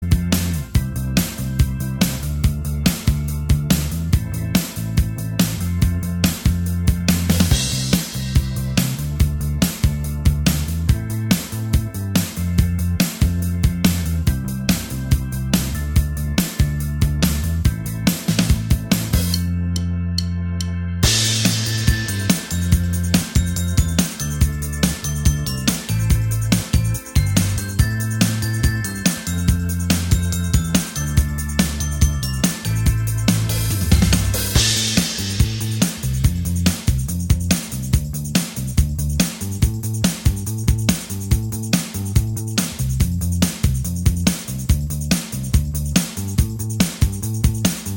Minus All Guitars Pop (2010s) 3:54 Buy £1.50